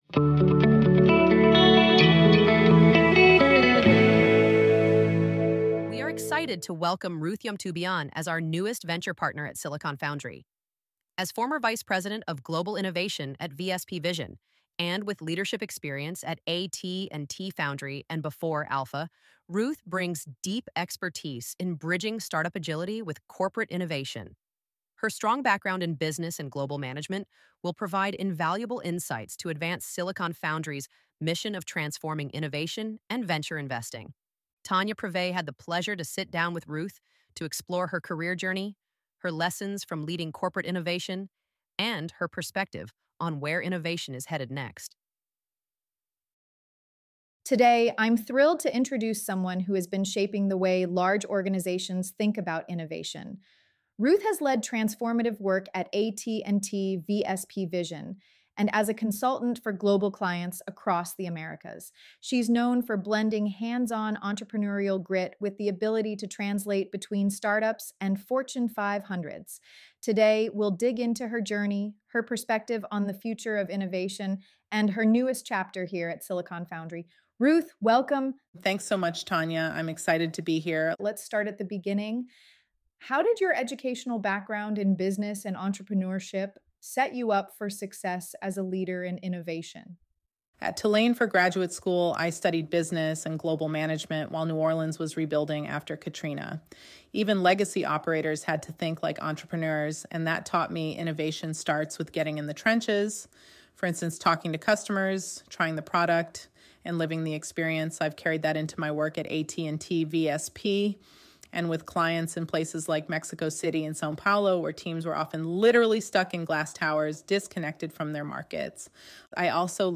Press play to listen to this conversation https